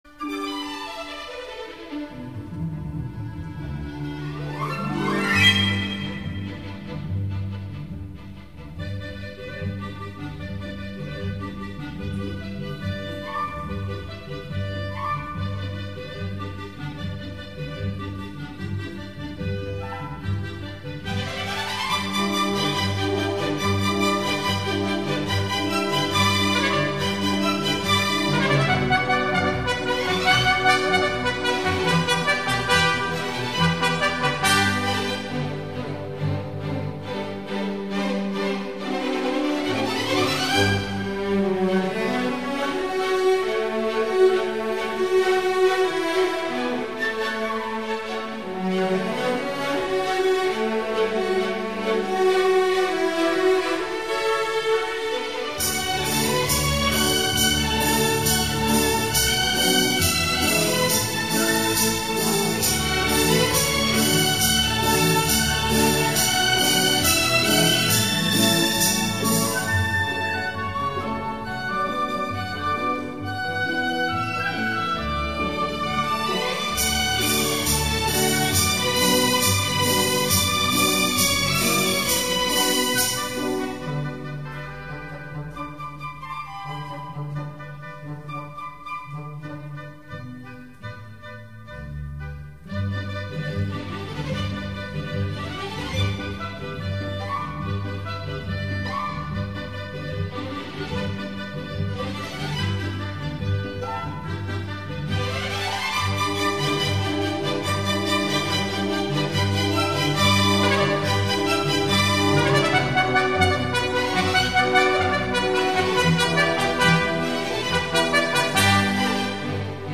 音乐类型: 民乐